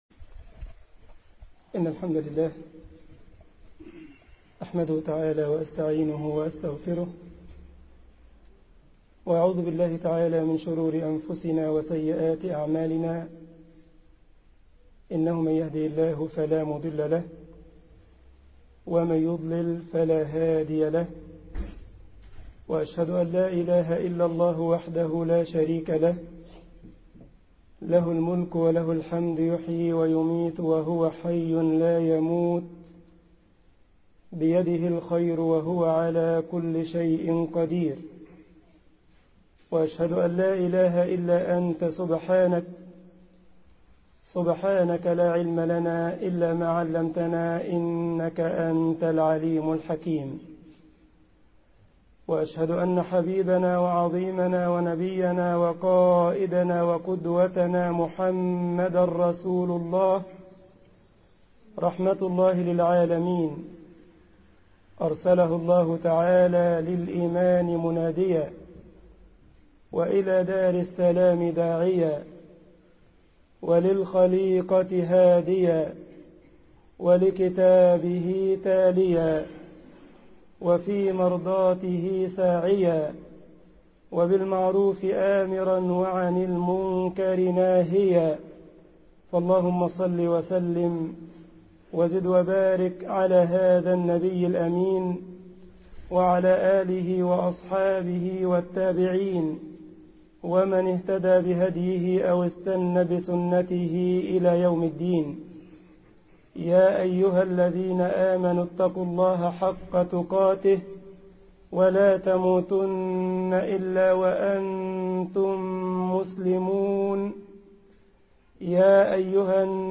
خطبة الجمعة
مسجد خالد بن الوليد بمدينة شتوتجارت ــ ألمانيا
Audio_Freitagsgebet-Stuttgart-ajaeb assadakat.mp3